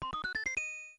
smw_princess_help.mp3